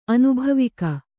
♪ anubhavika